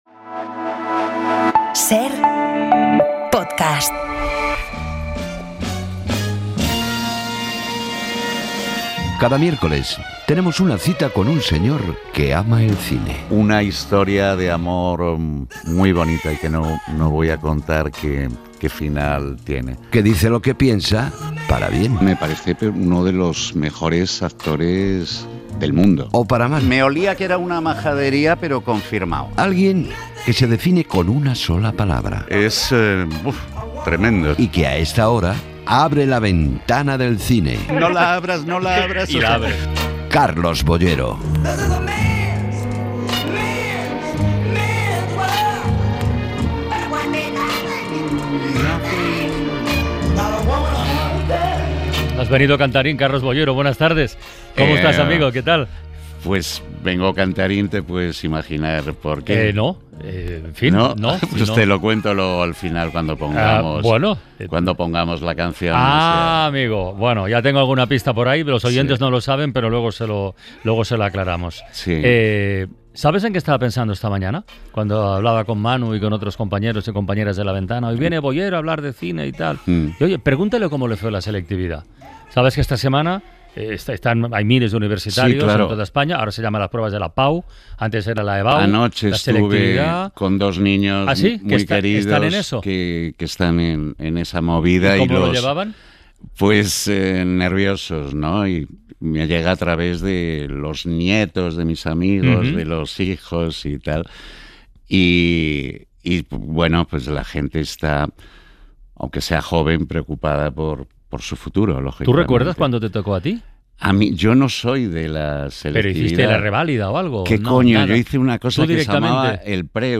Nuestro crítico de cine Carlos Boyero habla sobre la llegada a las salas de Sirat, la nueva película de Óliver Laxe protagonizada por Sergi López. Boyero, además, opina sobre el estreno de 'Pelé', el nuevo documental de Netflix sobre la figura del futbolista, y también sobre la falsa entrevista de Clint Eastwood.